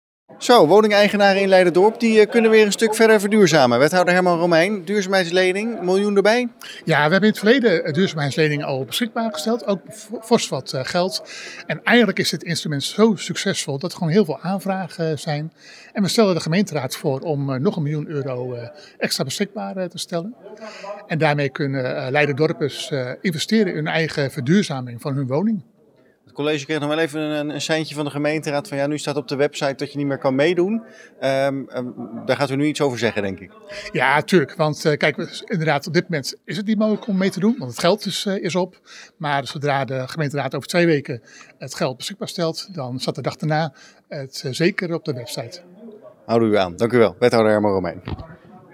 Wethouder Herman Romeijn over de duurzaamheidslening.
Herman-Romeijn-duurzaamheid.mp3